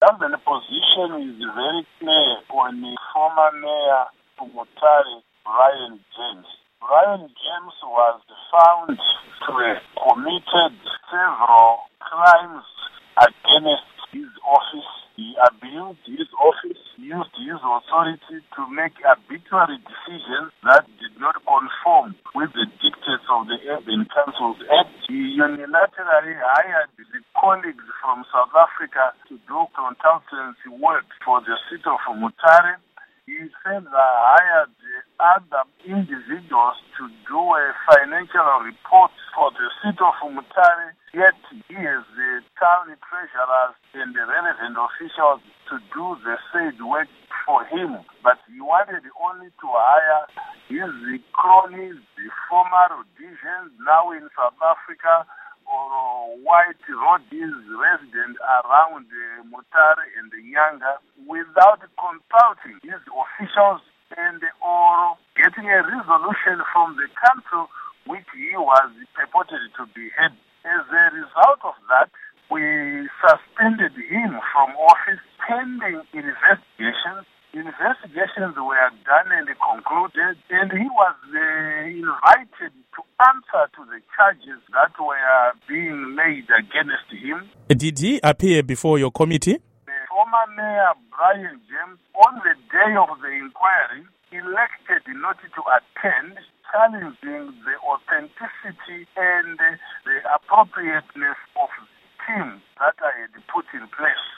Interview With Ignatius Chombo